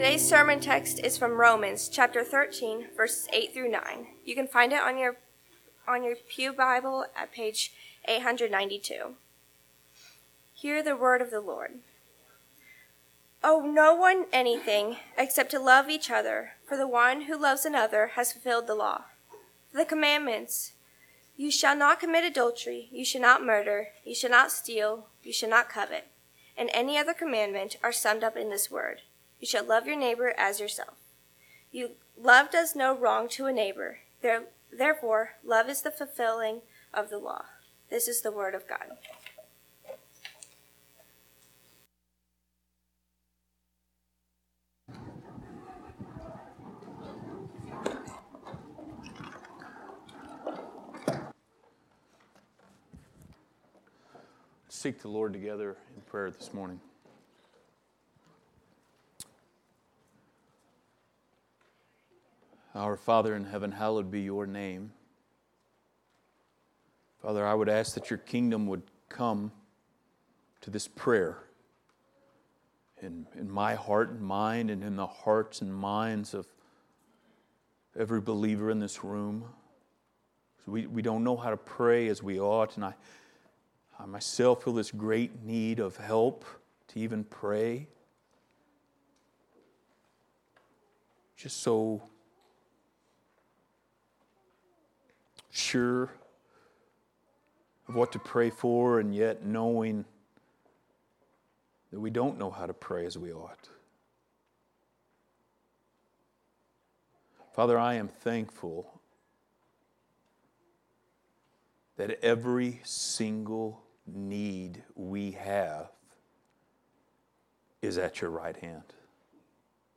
Passage: Romans 13:8-10 Service Type: Sunday Morning